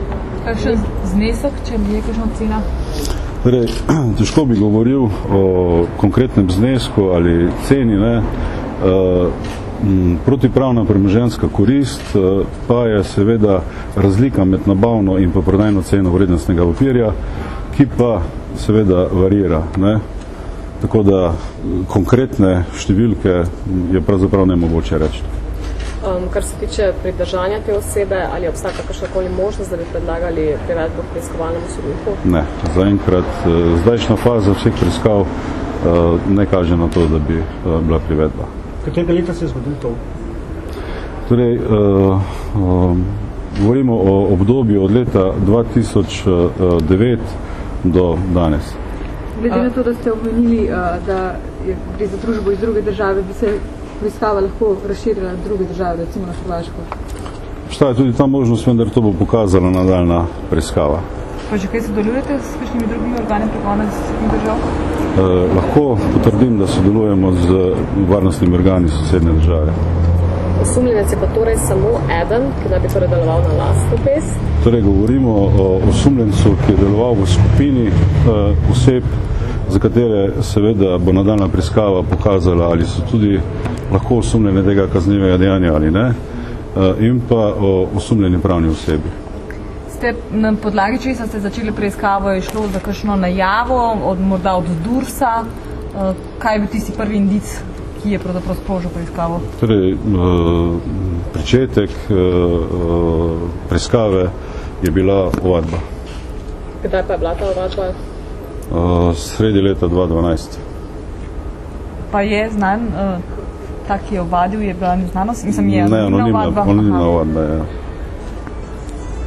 Novinarska vprašanja in odgovori (mp3)